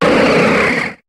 Cri de Relicanth dans Pokémon HOME.